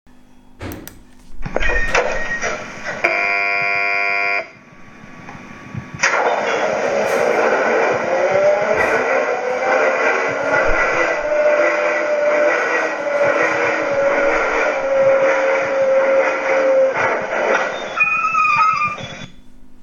Download Drehscheibensound, passend zu meinem Video Nr. 86
Drehscheibengeräusch.mp3
Drehscheibegeraeusch-1.mp3